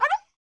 monster / stray_dog / damage_2.wav
damage_2.wav